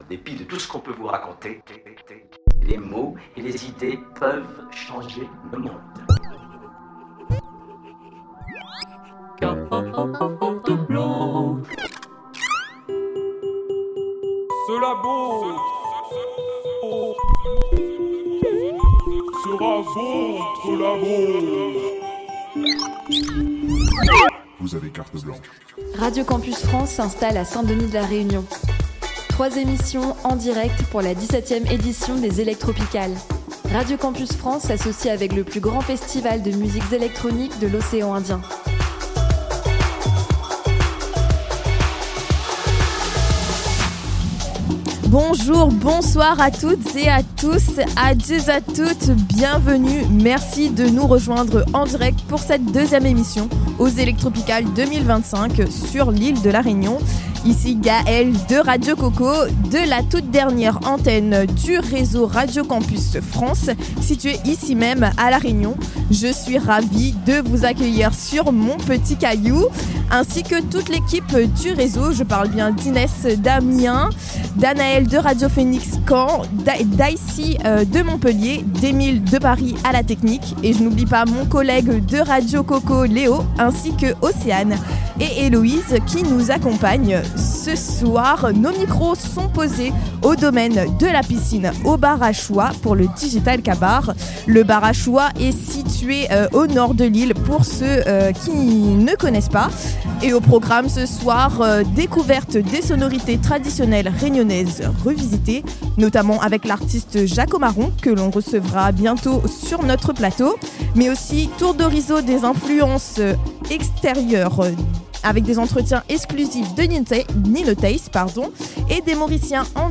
C’est au domaine La Piscine que Radio Campus France réalise son deuxième direct autour du renouveau des musiques traditionnelles de la Réunion.